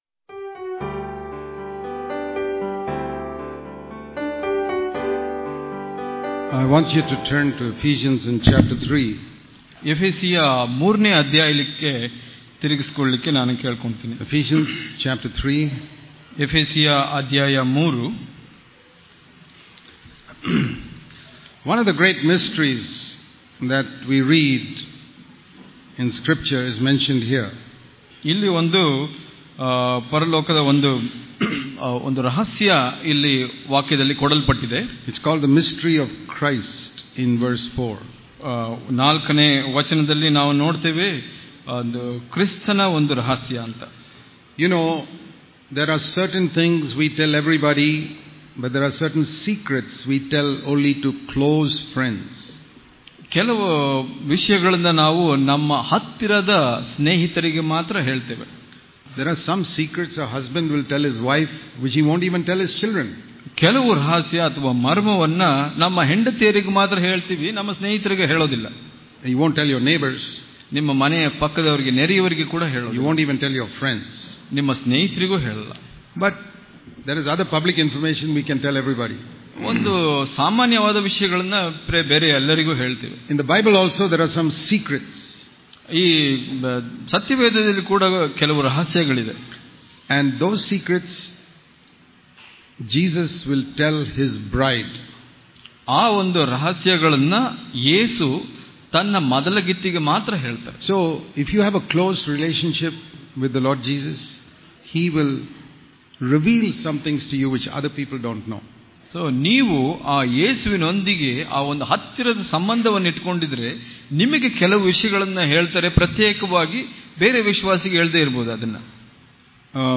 August 7 | Kannada Daily Devotion | Humility and Fellowship Daily Devotions